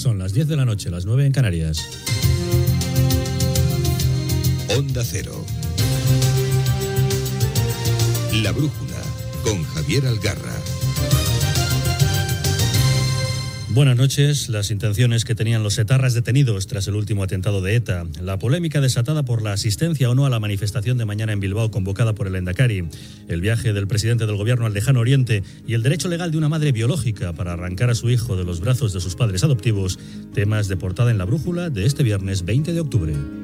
Hora, careta del programa i titulars.
Informatiu
FM